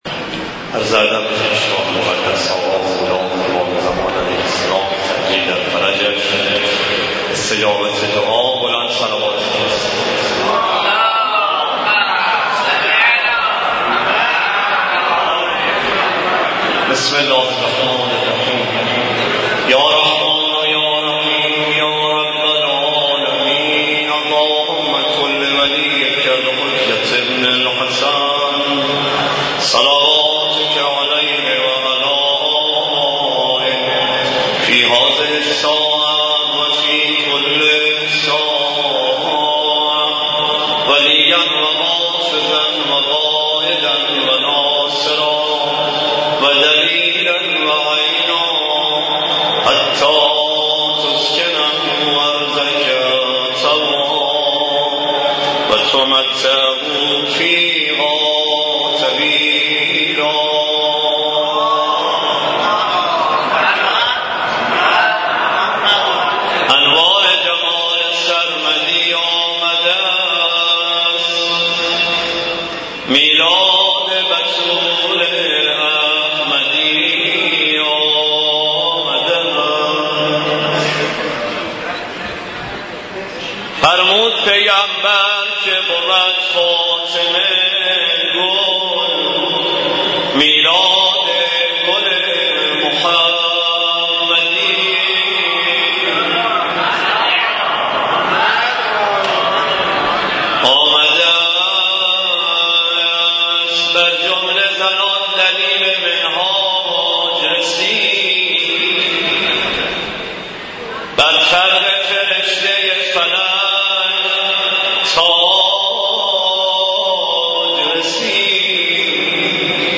برگزاری مدیحه سرایی میلاد باسعادت حضرت فاطمه زهرا سلام الله علیها در مسجد دانشگاه کاشان